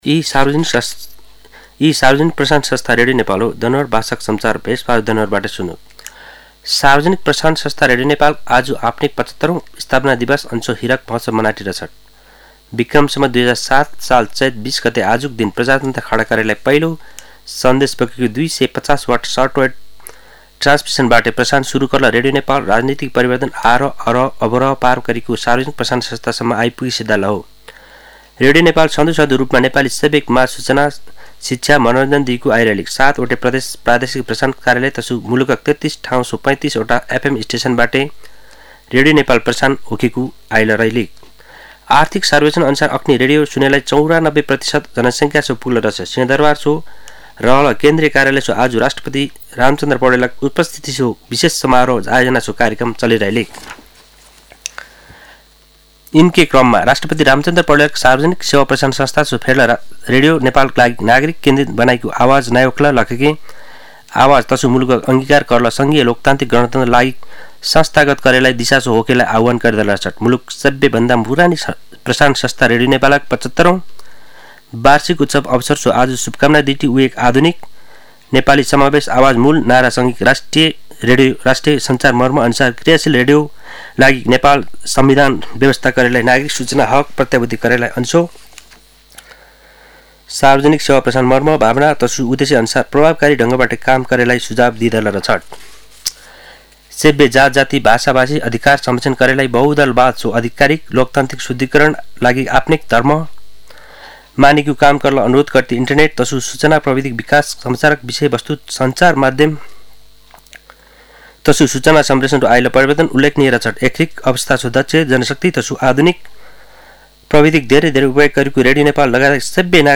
दनुवार भाषामा समाचार : २० चैत , २०८१
danuwar-news-1.mp3